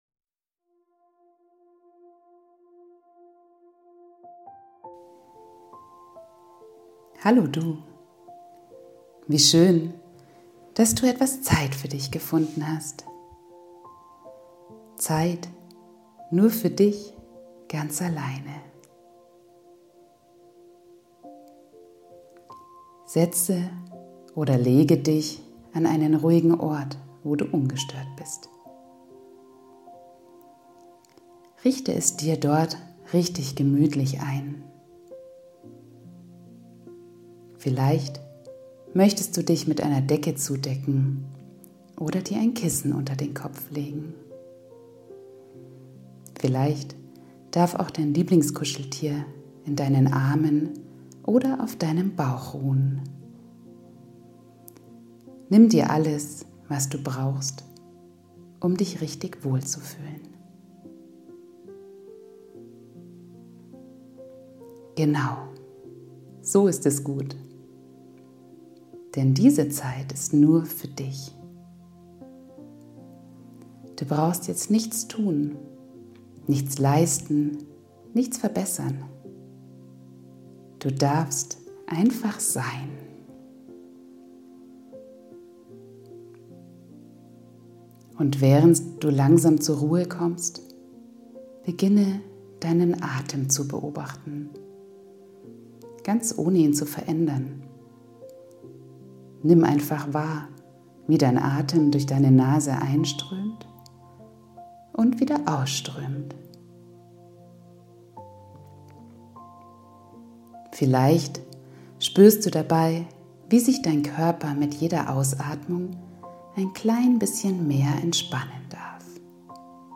Farbmeditation – ein Körperscan für Kinder
Eine geführte Fantasiereise, bei der Kinder ihren Körper Stück für Stück wahrnehmen lernen. Perfekt zum Runterkommen nach einem aufregenden Tag.
Farbmeditation.mp3